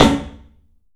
PABSNARE103L.wav